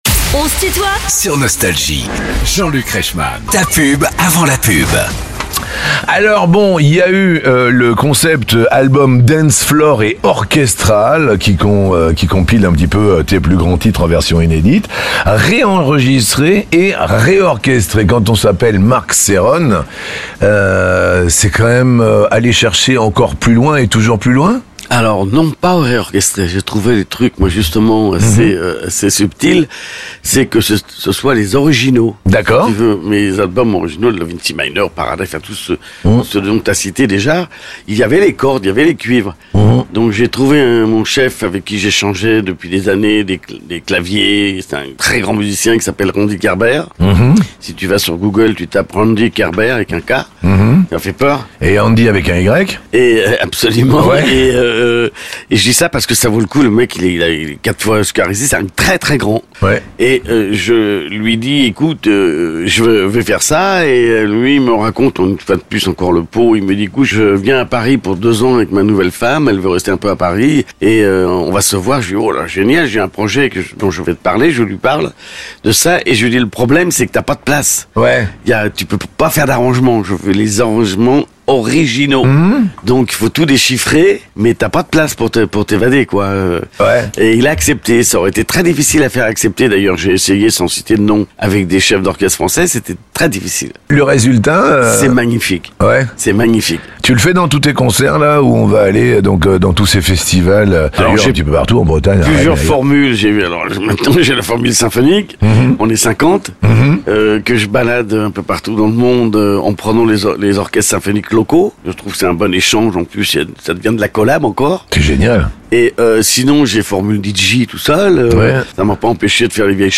Les plus grands artistes sont en interview sur Nostalgie.